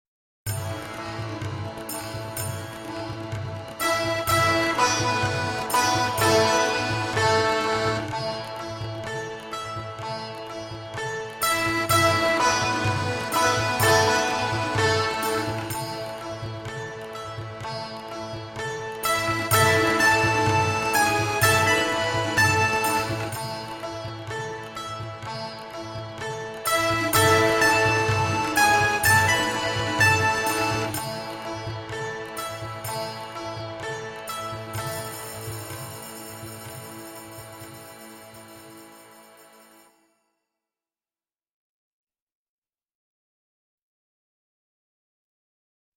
VS Saraswati (backing track)